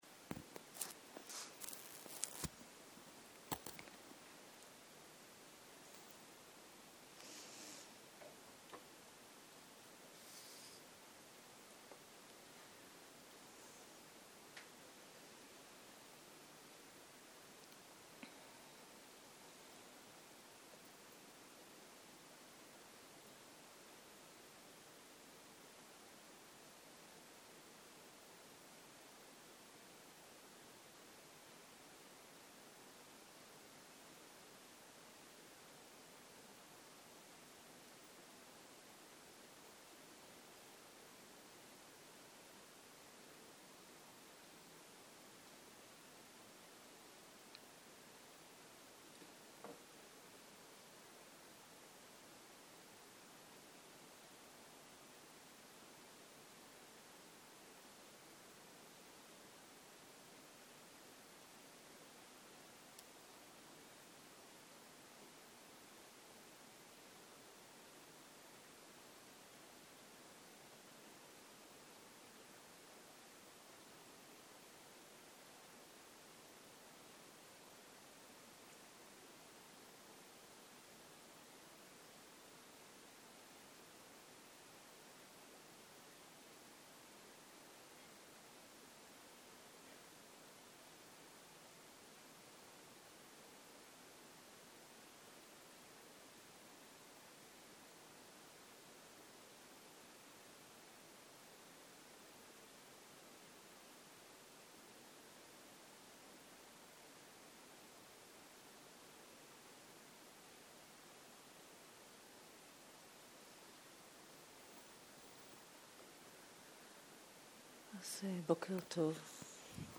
בוקר - מדיטציה מונחית - חיבור לתחושת הגוף - הקלטה 8
Dharma type: Guided meditation שפת ההקלטה